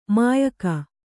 ♪ māyaka